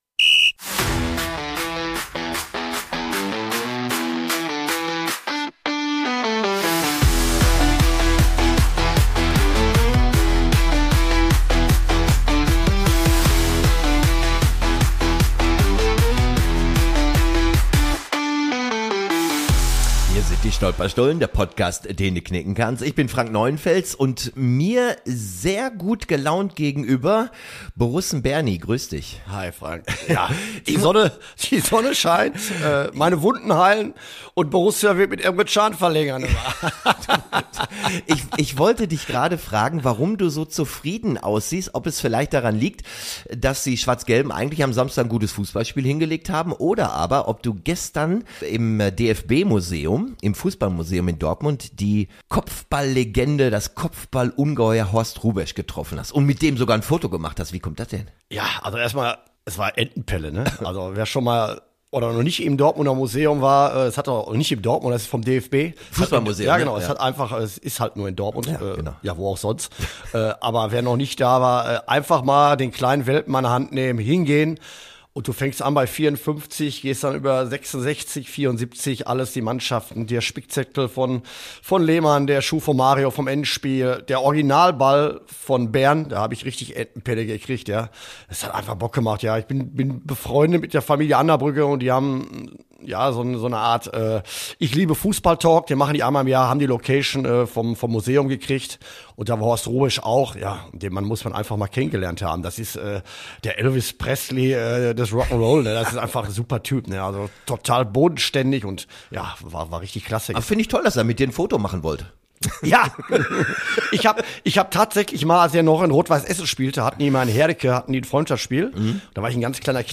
Die Analyse ist ehrlich, emotional – und stellenweise laut.
Ein 70er-Jahre-Kulthit wird nicht nur angestimmt – er wird zelebriert.
„Die Stolperstullen“ – der Podcast, in dem Niederlagen analysiert, Schlager gefeiert und 70er-Hits lauter gesungen werden als jede Fankurve.